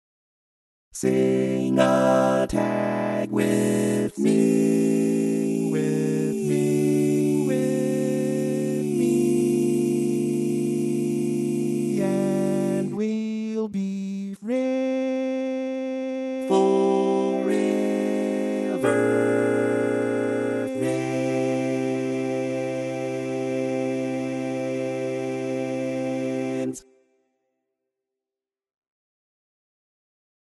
Key written in: C Major
How many parts: 4
Type: Barbershop
All Parts mix:
Learning tracks sung by